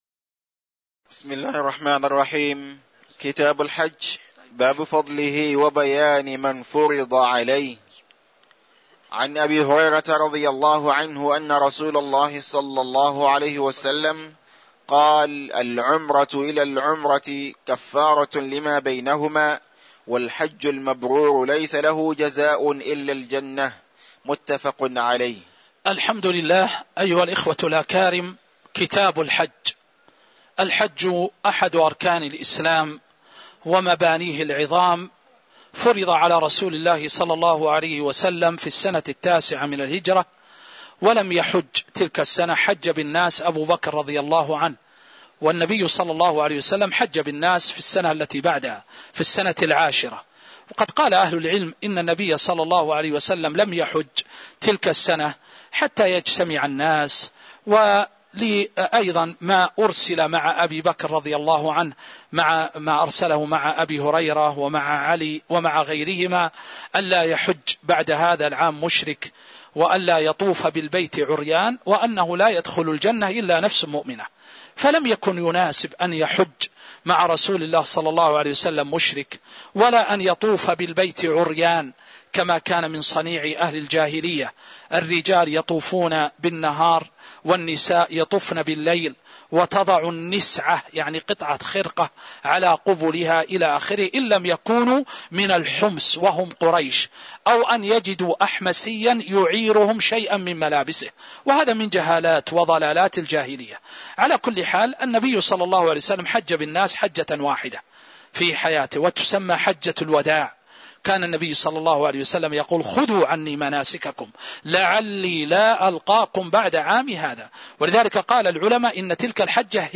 تاريخ النشر ٣ ذو القعدة ١٤٤٥ هـ المكان: المسجد النبوي الشيخ